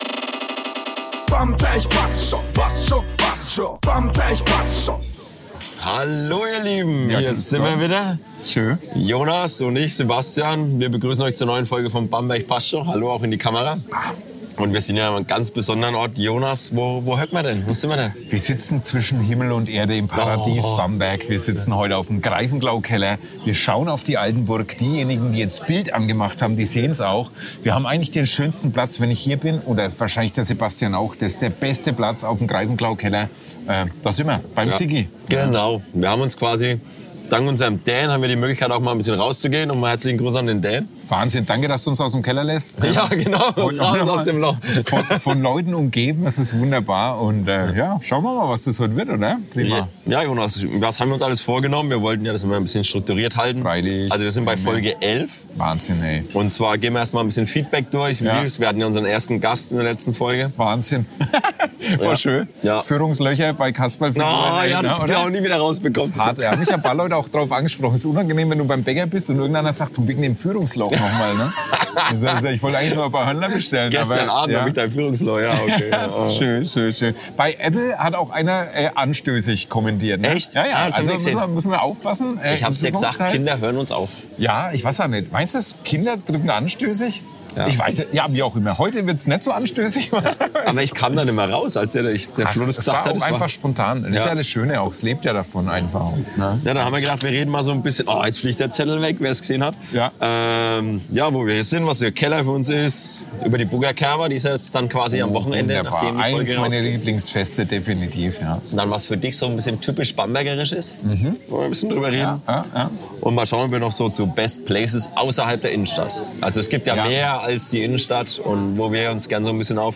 Es geht um die besondere Atmosphäre am Keller, die Buger Kerwa als Startschuss der Kerwasaison, typisch bambergerischen Humor, die Kunst der perfekten Brotzeit und die Frage, wie Bamberg seine Traditionen bewahrt und trotzdem lebendig bleibt. Dazu gibt’s persönliche Anekdoten, spontane Bestellungen und jede Menge fränkisches Herz.